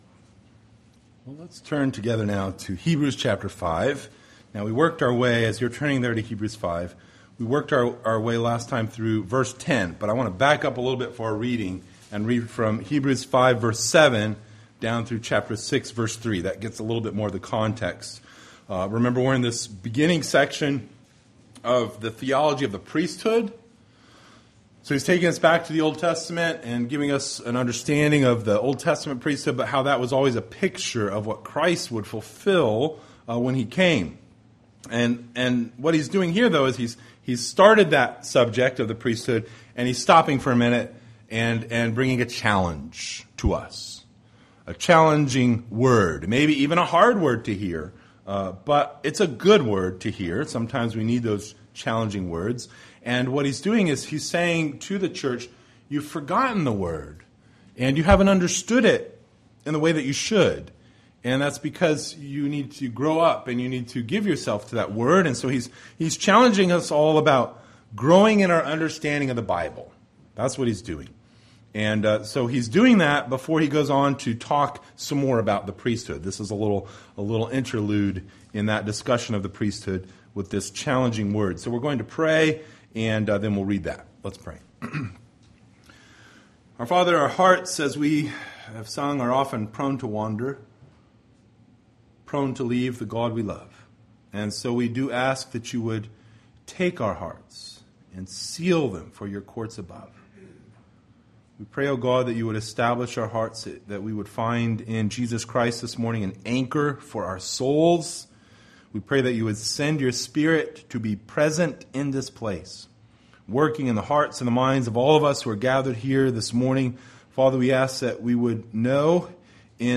Hebrews Passage: Hebrews 5:7-6:3 Service Type: Sunday Morning Related « Of God and Of the Holy Trinity